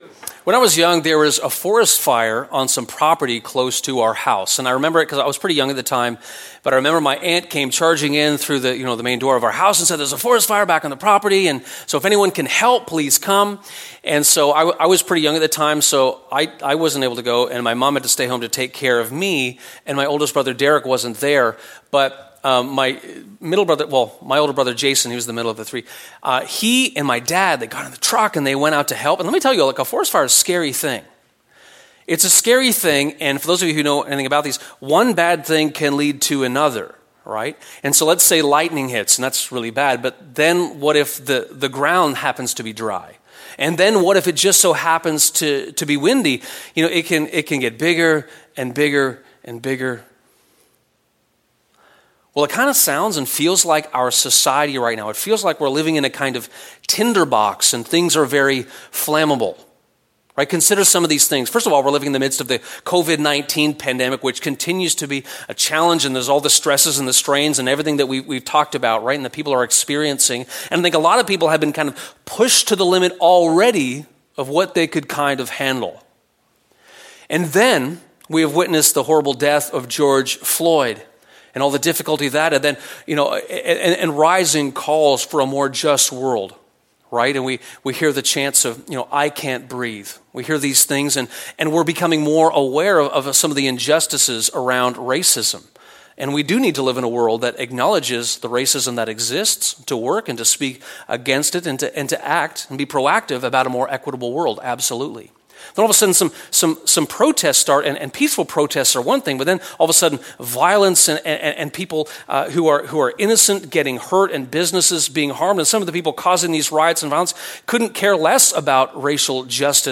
Gasoline or Love in a Tinderbox [Sermon]